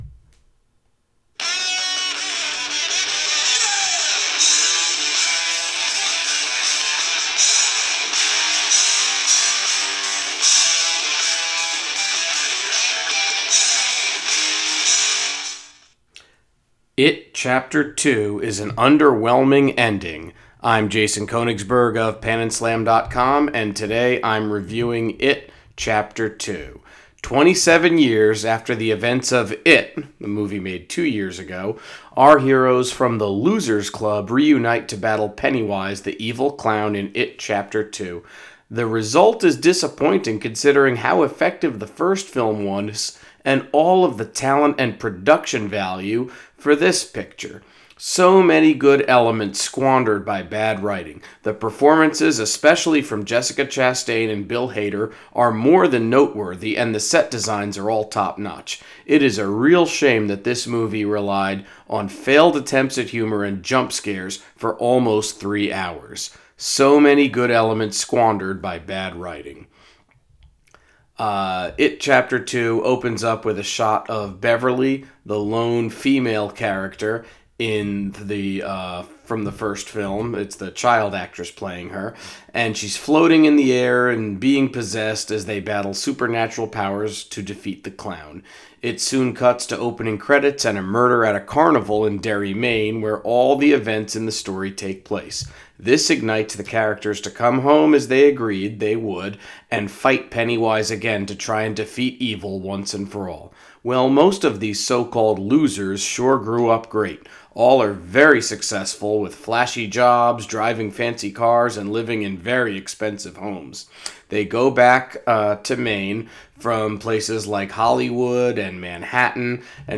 Movie Review: It Chapter 2